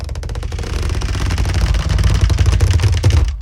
spiderweb.ogg